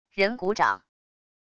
人鼓掌wav音频